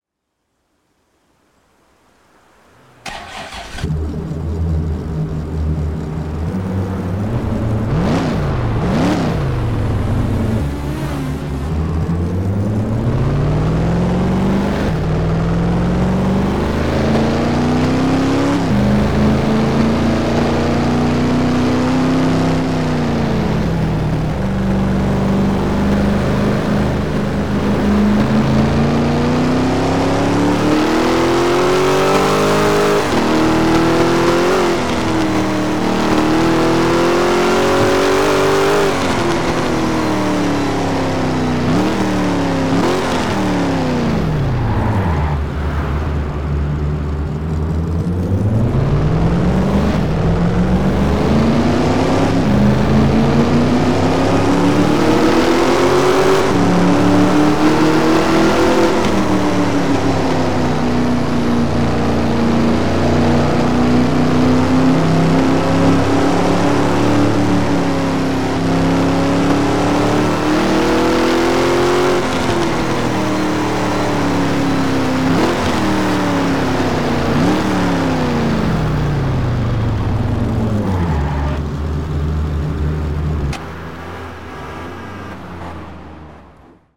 - Ford GT
- Ford Mustang